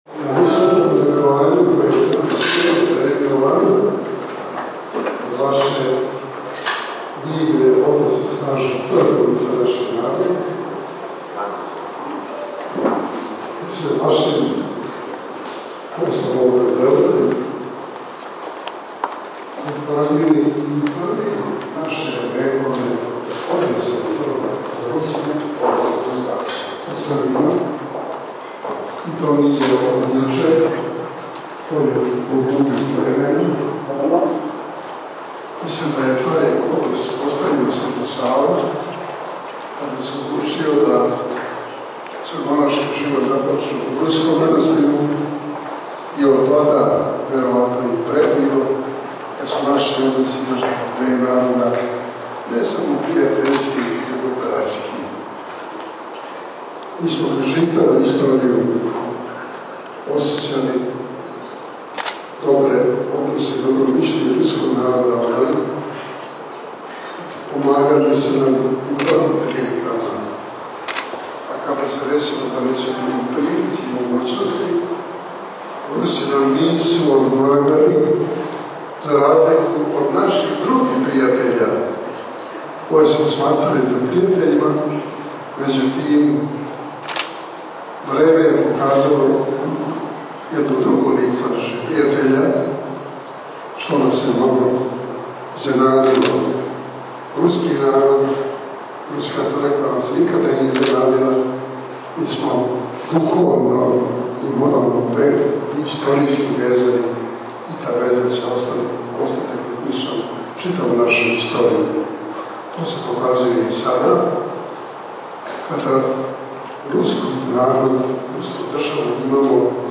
Обраћање Патријарха Иринеја на свечаности у Руском дому